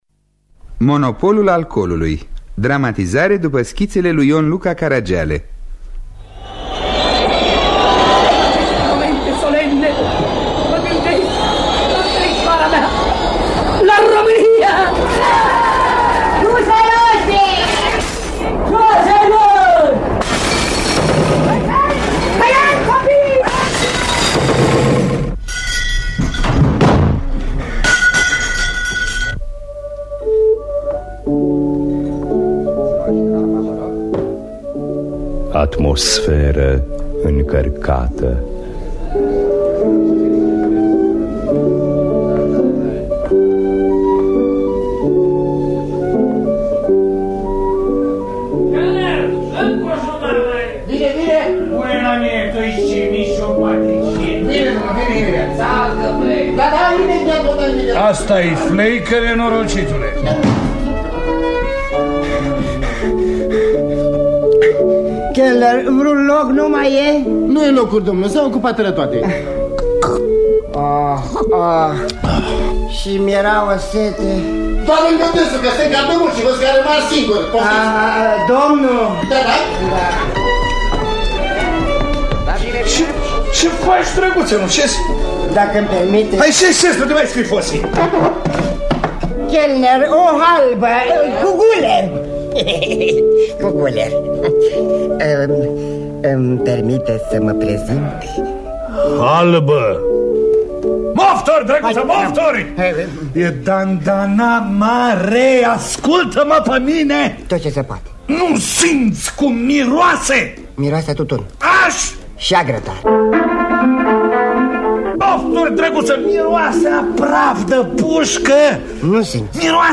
Dramatizarea radiofonică de Ion Cojan după schiţele “Atmosferă încarcată” şi “Cam târziu”.
Înregistrare din anul 1967.